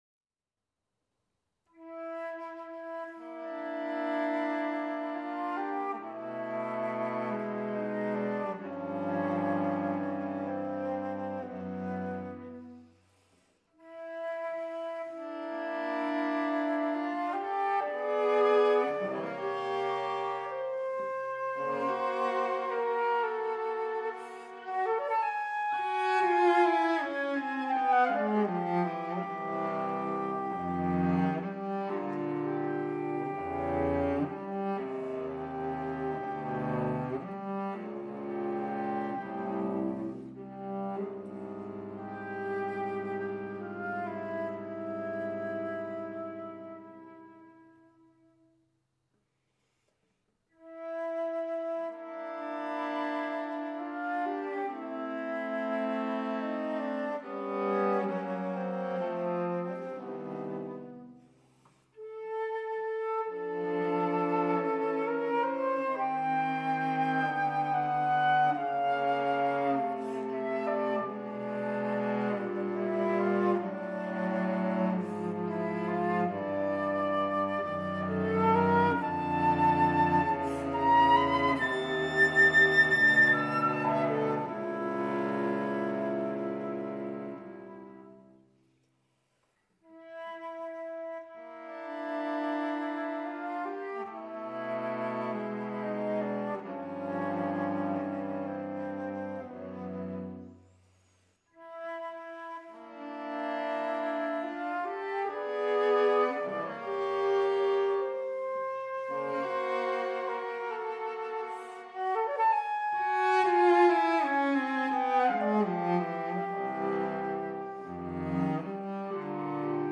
II. Adagio